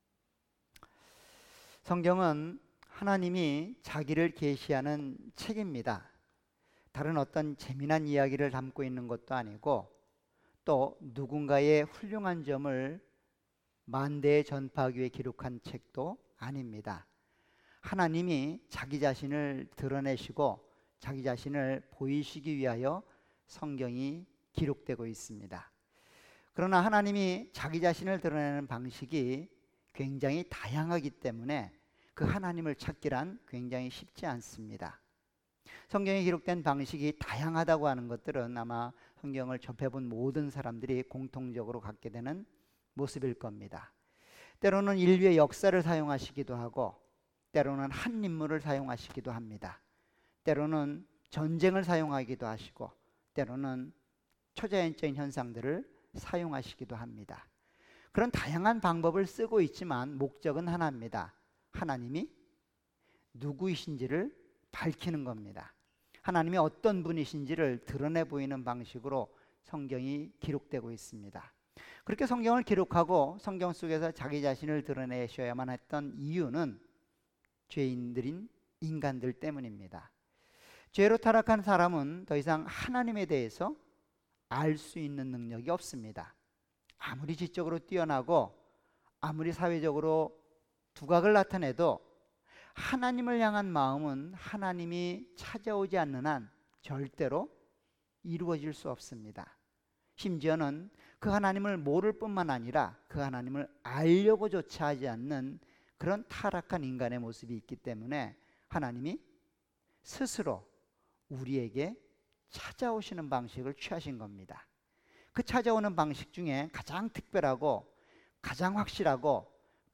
All Sermons
Series: 수요예배.Wednesday